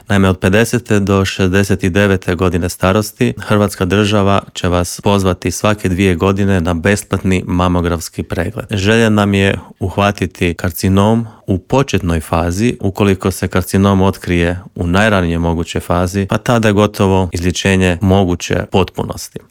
ZAGREB - Pred nama je prvi dan nastave i nova školska godina, a Media Servis u intervjuima je ovoga tjedna s pročelnikom Lukom Jurošem i ministrom obrazovanja Radovanom Fuchsom provjerio je li sve spremno te što đake i roditelje očekuje u novoj godini.